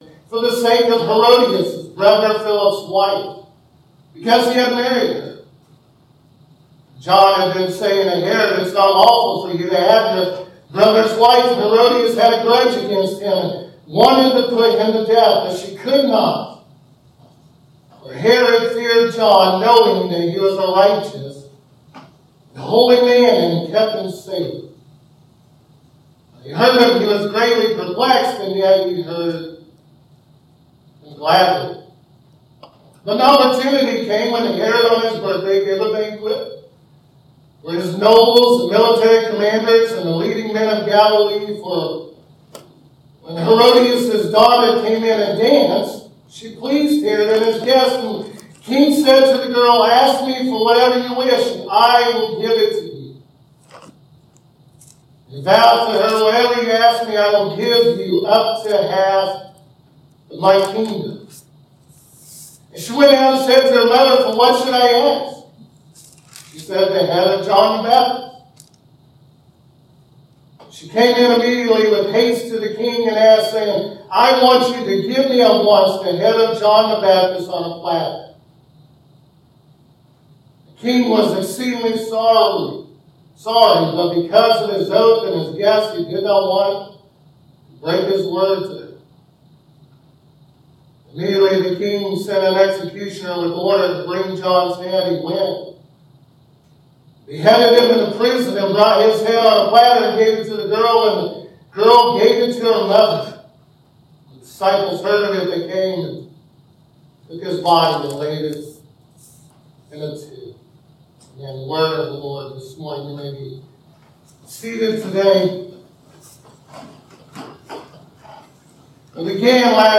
Mark 6:14-29 Service Type: Sunday Morning John the Baptist pays the ultimate price for telling the truth about Herod.